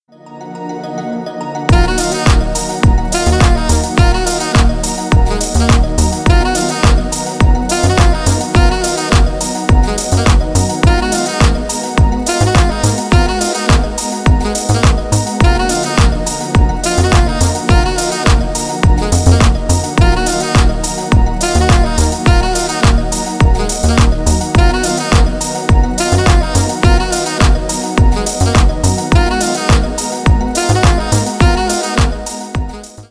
• Качество: 320 kbps, Stereo
Танцевальные
клубные
без слов